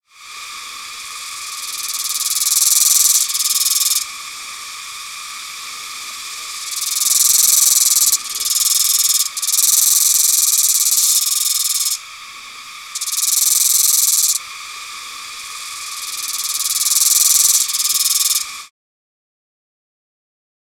Cicadas emit species-specific tymbalizations (songs), which are available online as supporting material to this volume (111 downloadable audio tracks).
Cicada tymbalization downloads